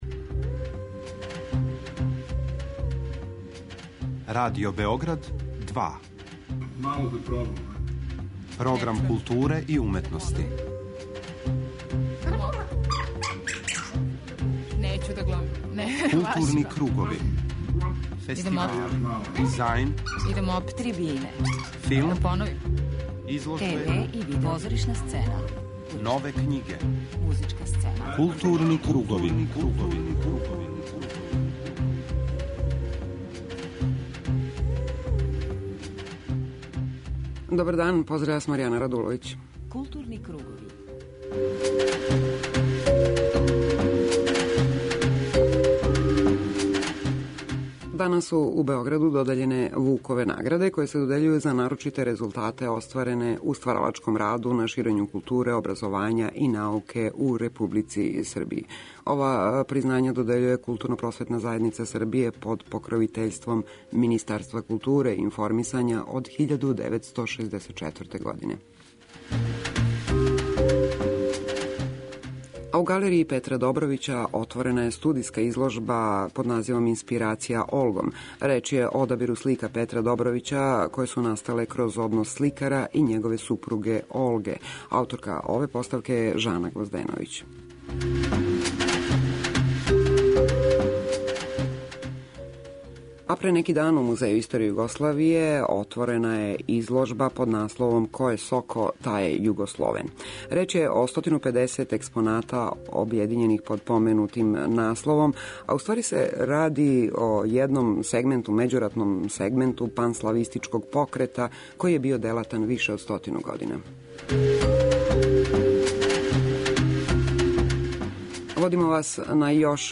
преузми : 41.26 MB Културни кругови Autor: Група аутора Централна културно-уметничка емисија Радио Београда 2. Како би што успешније повезивали информативну и аналитичку компоненту говора о култури у јединствену целину и редовно пратили ритам културних збивања, Кругови имају магазински карактер.